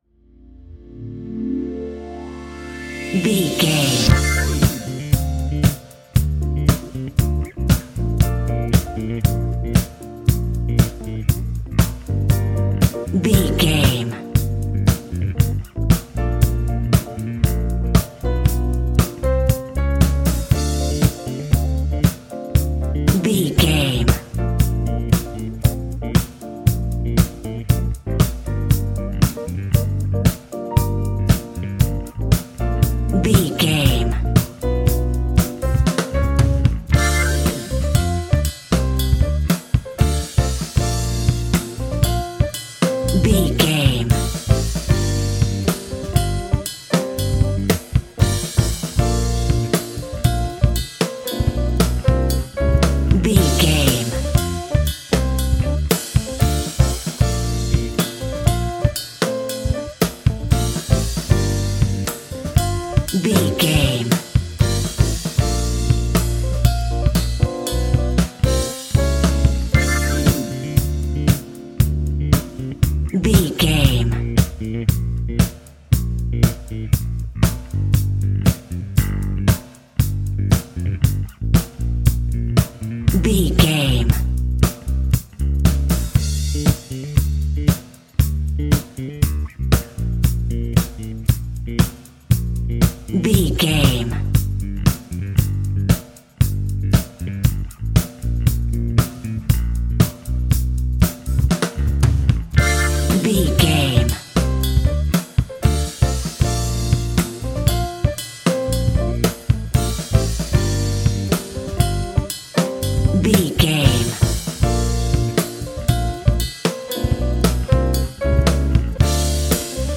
Ionian/Major
A♯
house
synths
techno
trance
instrumentals